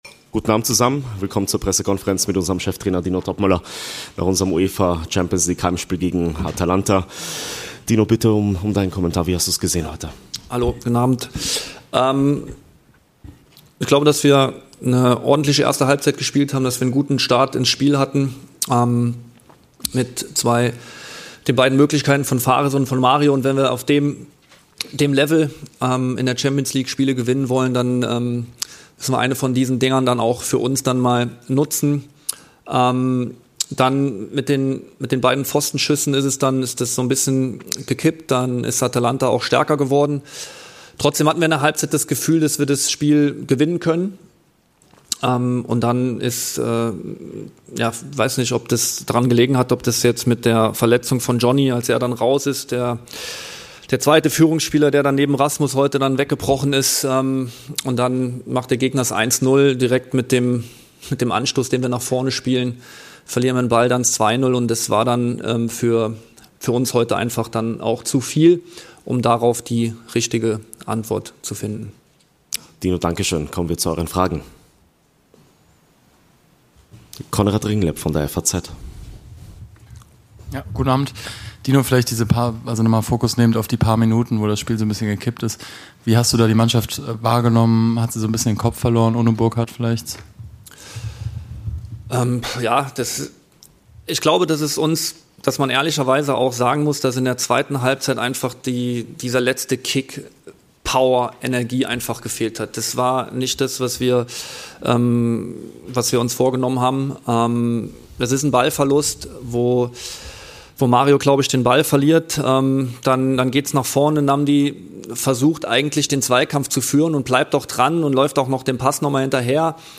Unser Cheftrainer Dino Toppmöller spricht auf der Pressekonferenz